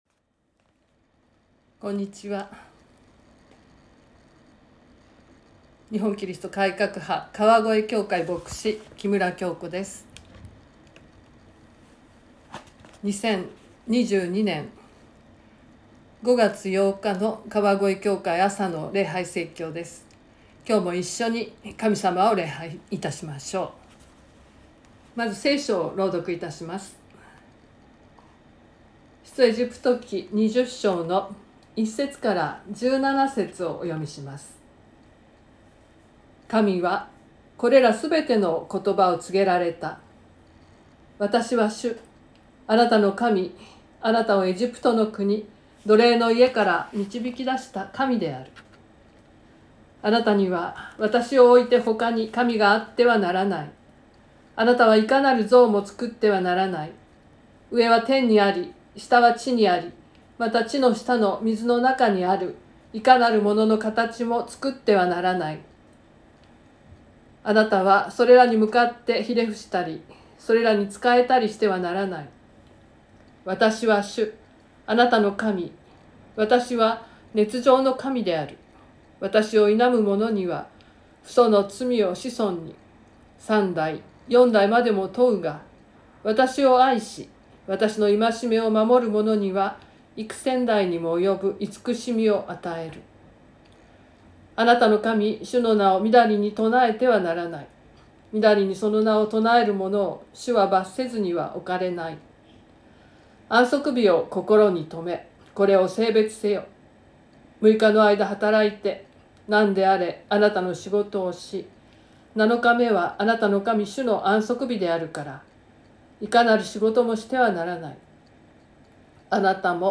2022年05月08日朝の礼拝「私は主 あなたの神」川越教会
説教アーカイブ。